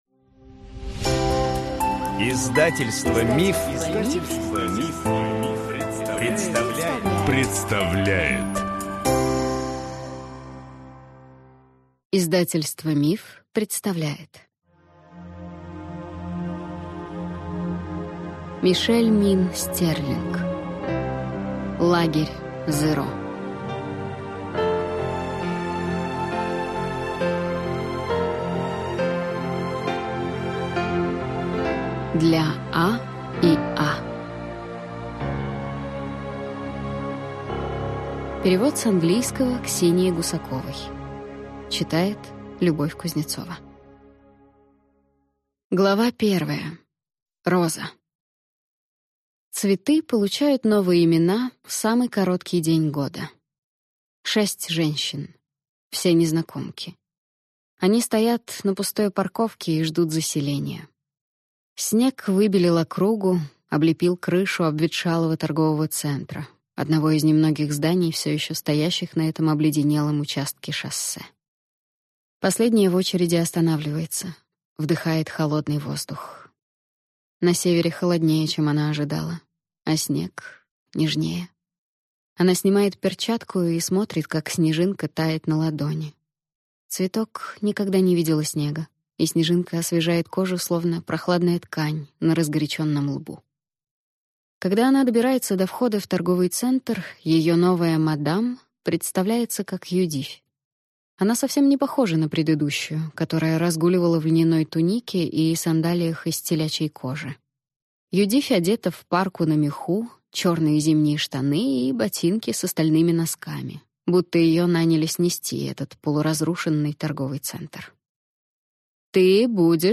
Аудиокнига Лагерь «Зеро» | Библиотека аудиокниг